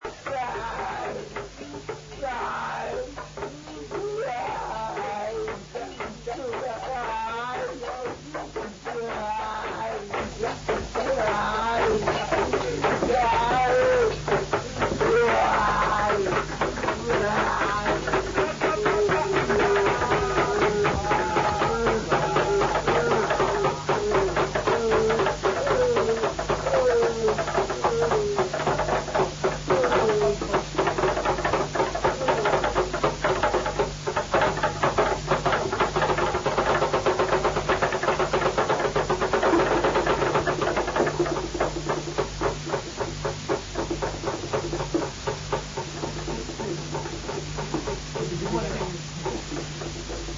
Drums & cries(197 KB)